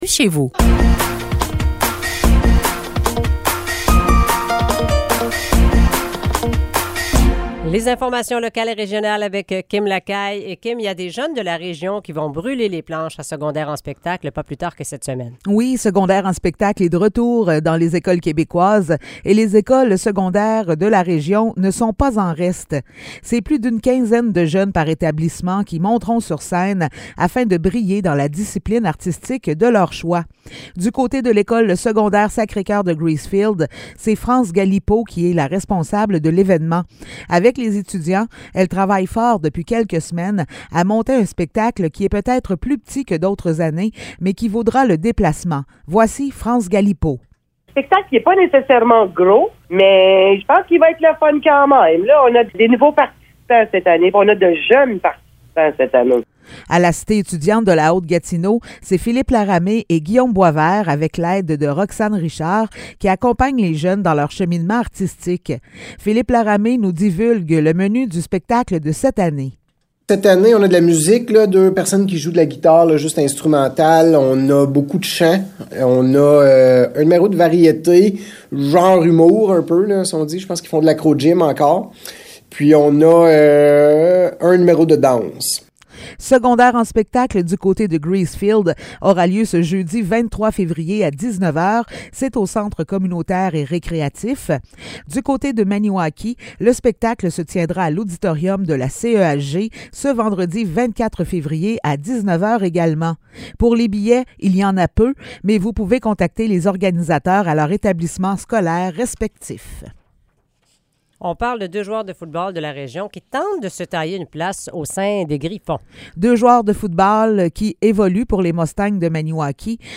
Nouvelles locales - 22 février 2023 - 8 h